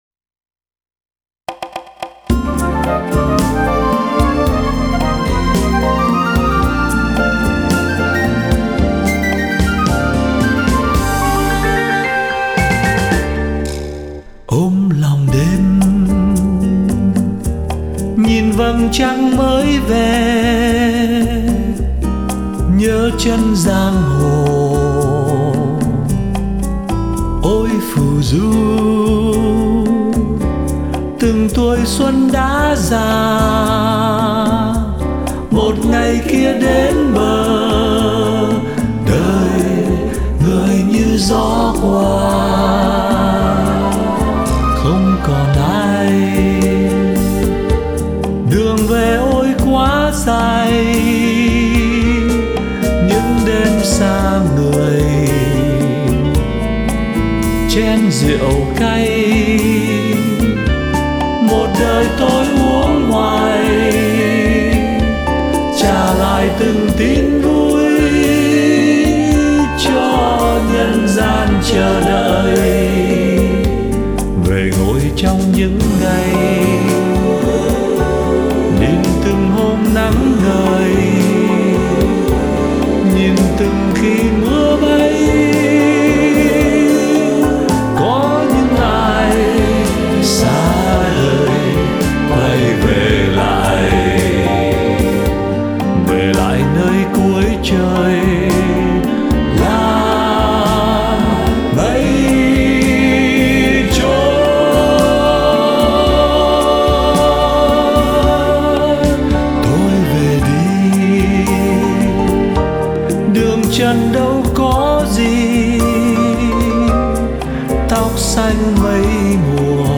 Giọng hát đam mê trực tiếp đi vào lòng người nghe.